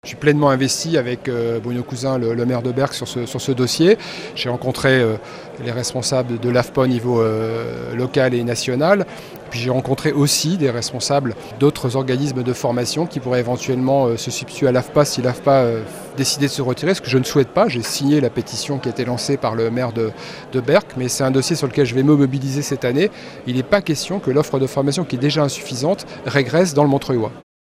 Lors de sa cérémonie de vœux, lundi soir à Ecuires, Daniel Fasquelle, député du Montreuillois a assuré les élus et la population de son investissement dans le dossier de fermeture programmée de l'Afpa de Berck.